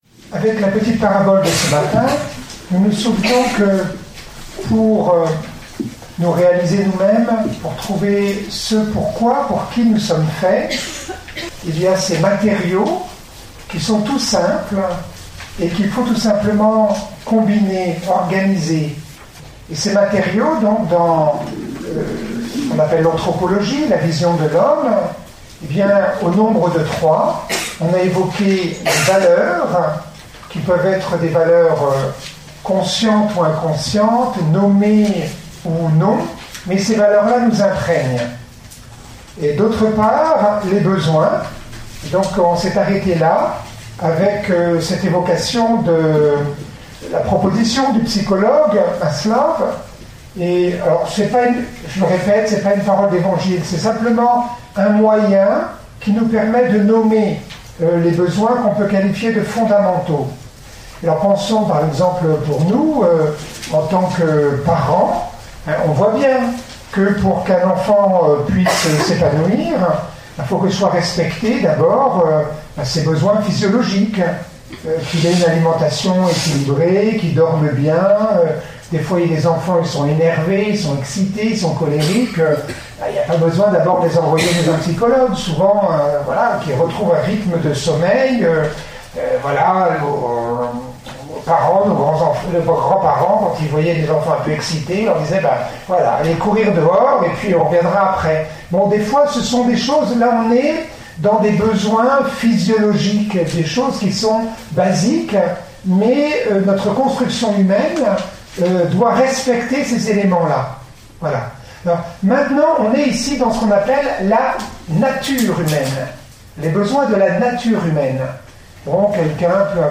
Retraite de discernement Enregistr� � Pensier (Suisse) du 09 au 11 septembre 2016Int